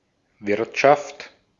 Ääntäminen
Ääntäminen France: IPA: /me.naʒ/ Haettu sana löytyi näillä lähdekielillä: ranska Käännös Ääninäyte Substantiivit 1.